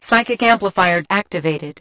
The voices are low quality than usual.
eva_alliedpsychicamplifieractivated_102.wav